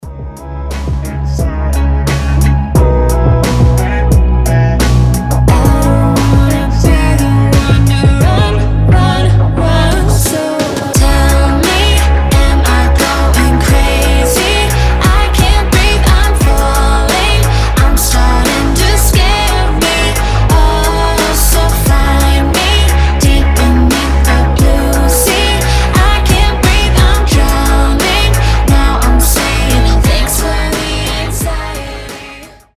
• Качество: 320, Stereo
атмосферные
красивый женский голос
alternative